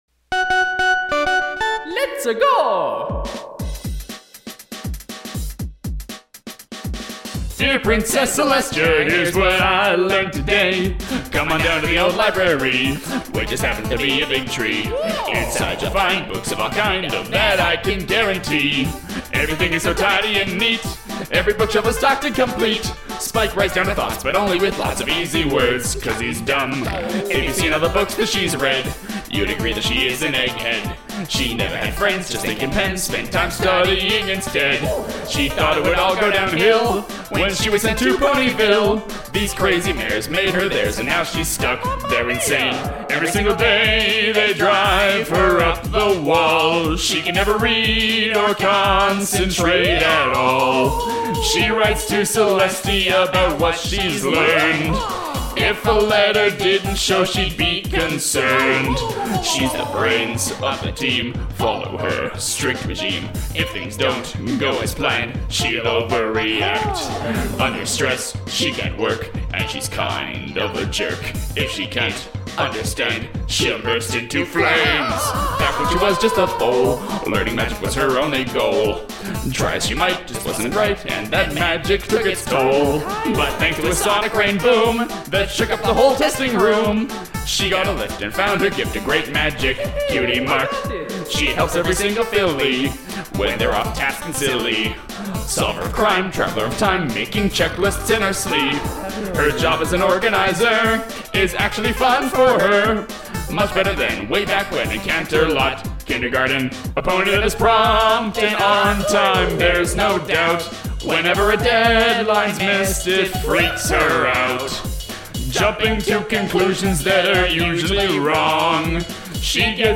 Hey! It's-a cover!